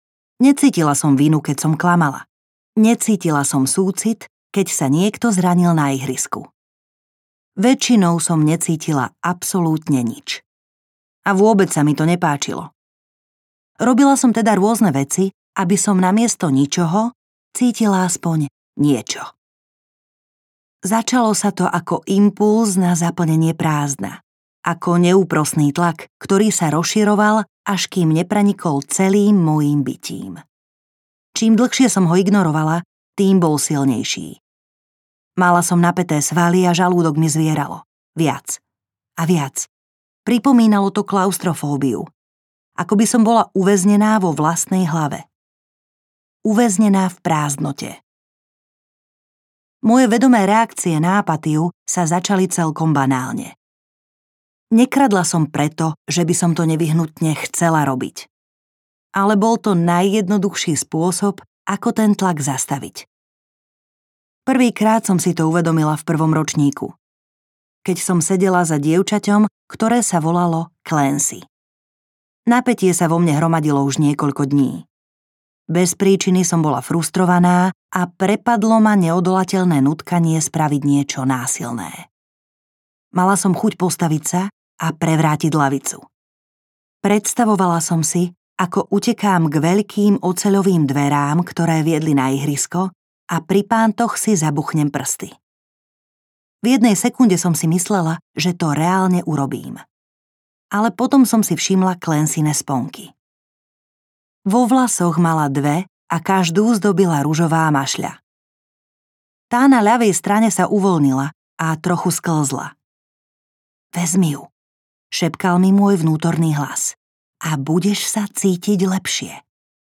Sociopatka audiokniha
Ukázka z knihy
• InterpretLujza Garajová Schrameková